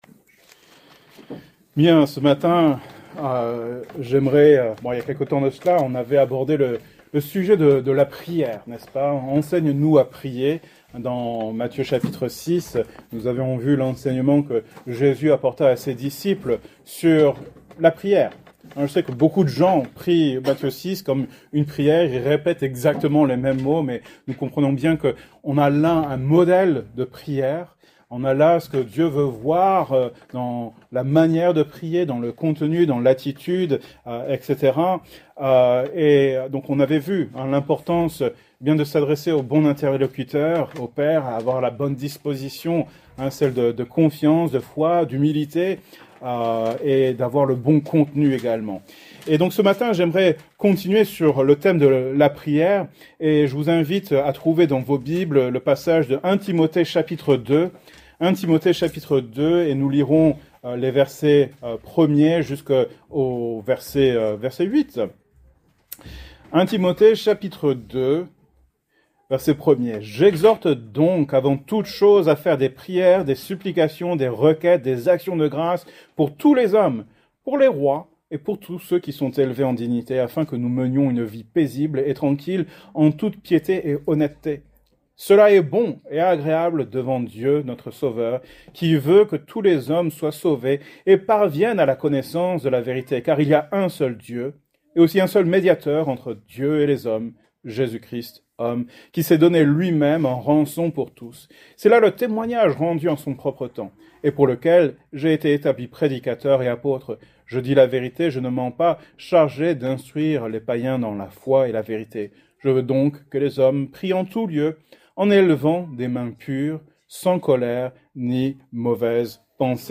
Thème: Prières; Évangélisation Genre: Prédication